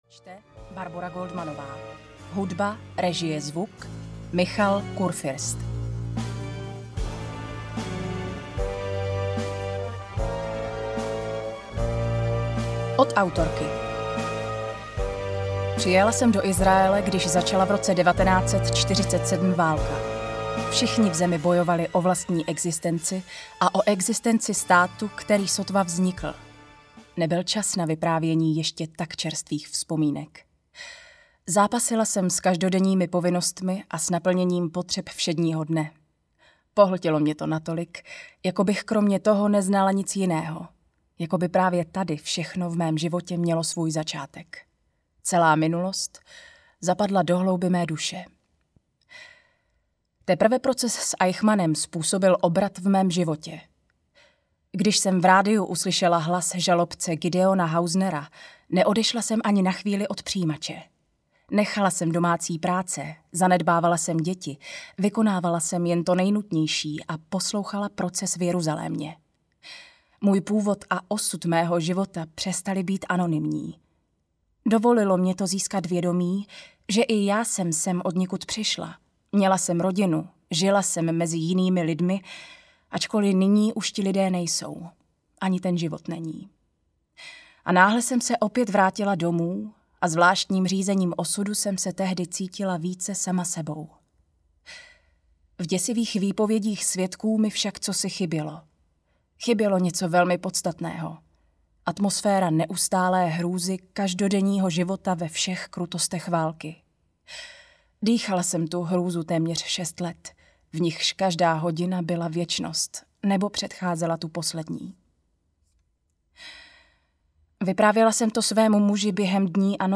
Naděje umírá poslední audiokniha
Ukázka z knihy